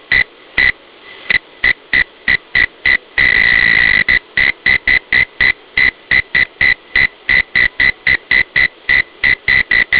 Common Mole Crickets and their Calling Songs - Gryllotalpa pluvialis
Love songs from male crickets are a series of loud, deep tones repeated regularly about two times per second. But it is not quite regular when start.
Non- regular pattern, recorded at the beginning of the song, 10 sec.
Love songs from male crickets are a series of loud, deep-toned chirps, like crick-crick, crick-crick, crick-crick repeated regularly about two times per second.
MoleSongS.wav